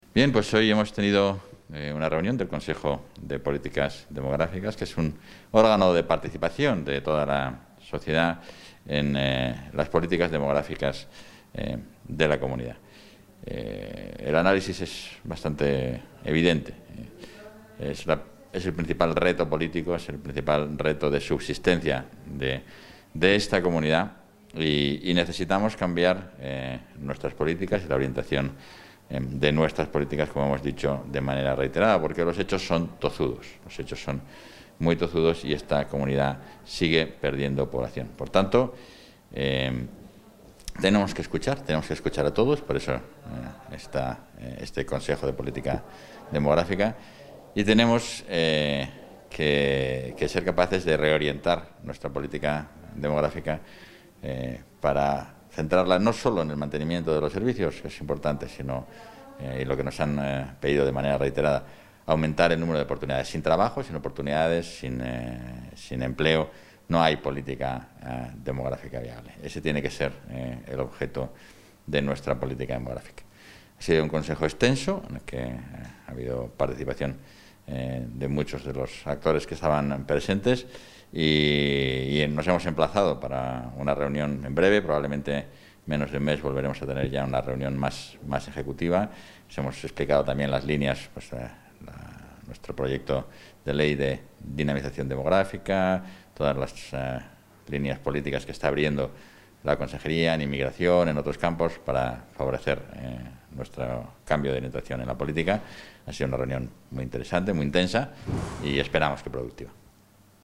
Valoración del vicepresidente de la Junta.